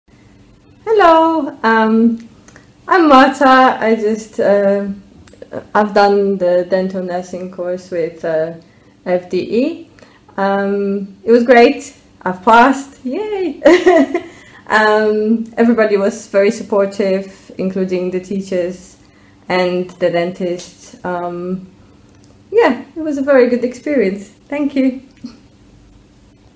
Below, you’ll find a collection of testimonials from past participants.
Audio Testimonials